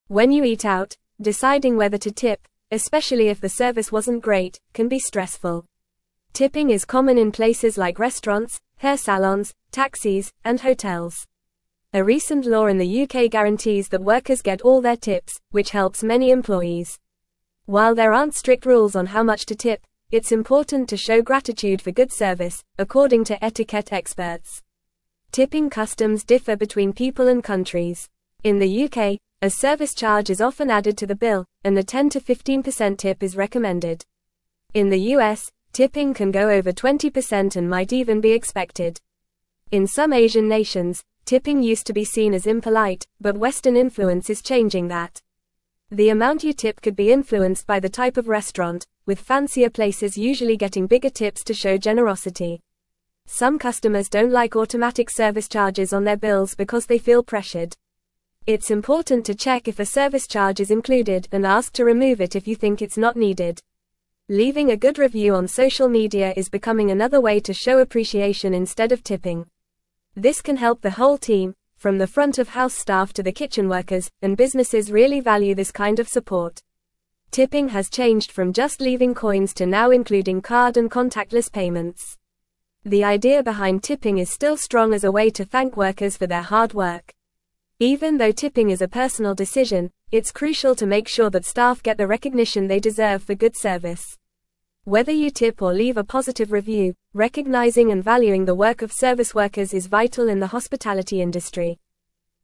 Normal
English-Newsroom-Upper-Intermediate-FAST-Reading-Navigating-Tipping-Etiquette-Acknowledging-Good-Service-Graciously.mp3